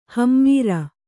♪ hammīra